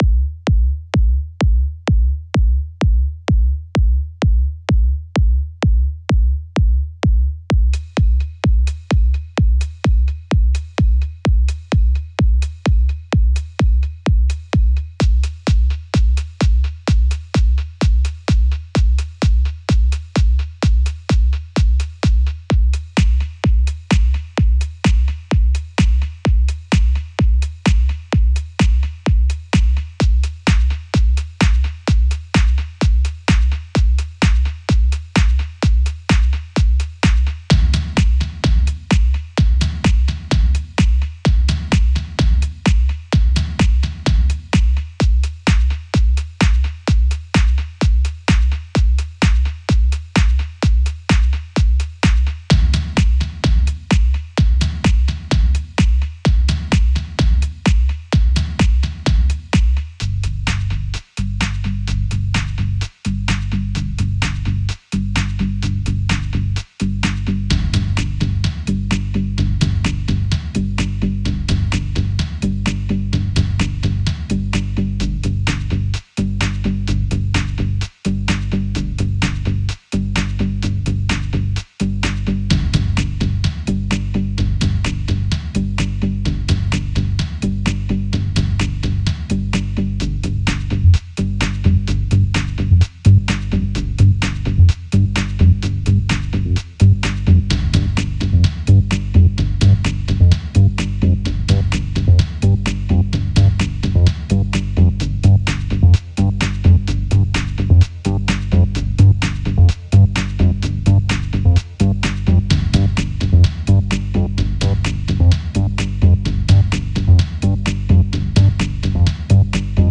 Al-Graia'at-Brücke über dem Tigris Bagdad Irak